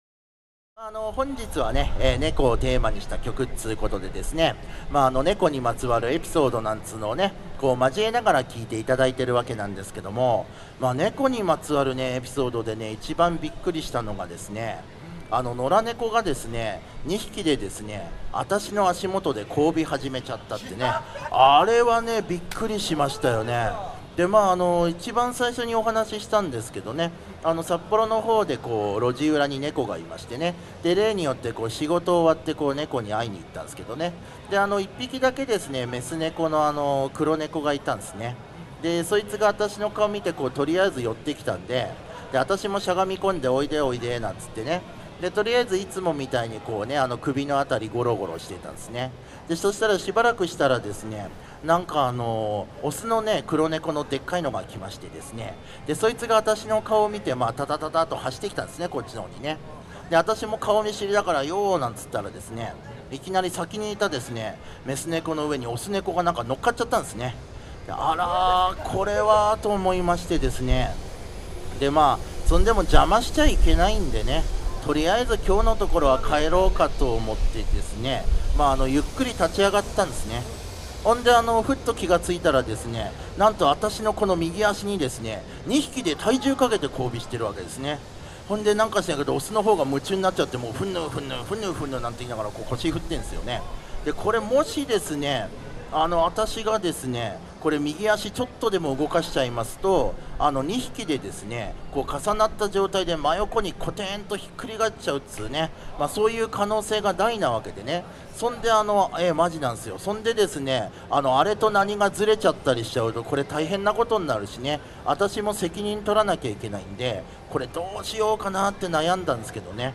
東京ストリートコーナーズ“ロジヨコライブ”レポート